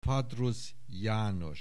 Aussprache Aussprache
FADRUSZJANOS.wav